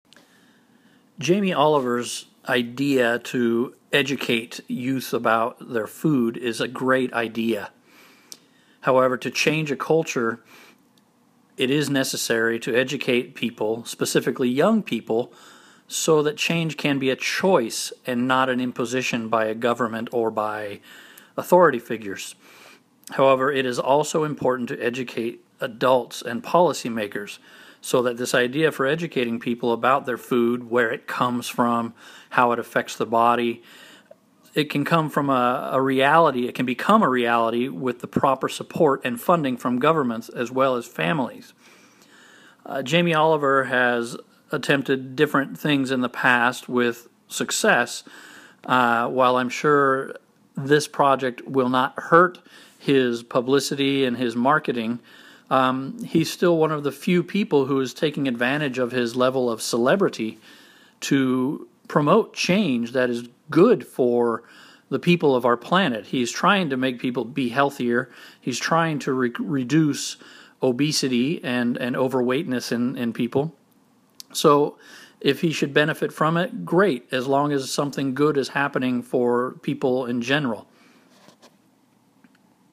The person is giving an opinion about ´s idea.